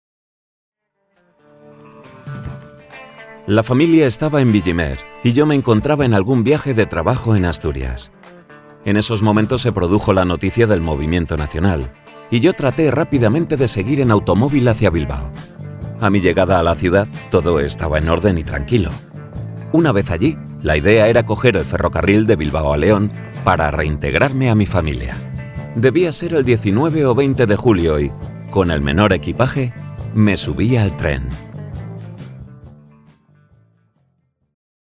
Narración
Soy locutor y actor de doblaje en España.
Neumann Tlm 103